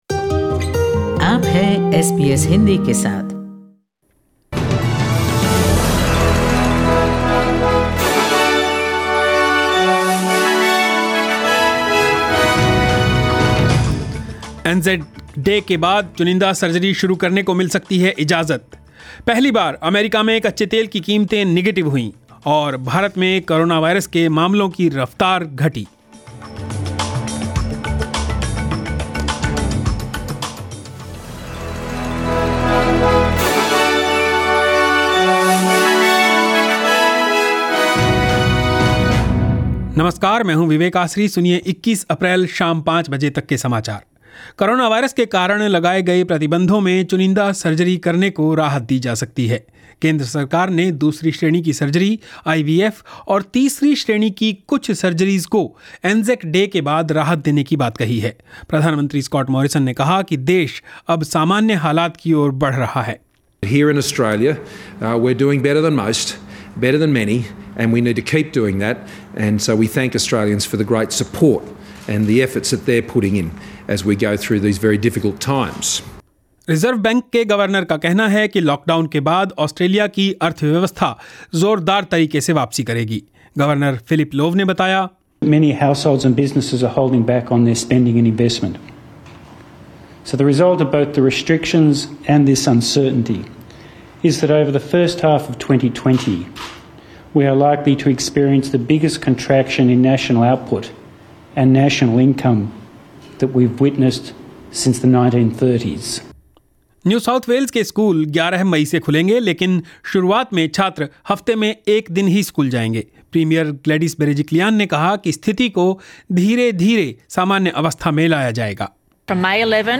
News in Hindi 21 April 2020